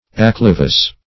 acclivous - definition of acclivous - synonyms, pronunciation, spelling from Free Dictionary
Search Result for " acclivous" : The Collaborative International Dictionary of English v.0.48: Acclivous \Ac*cli"vous\ (#; 277), a. [L. acclivis and acclivus.]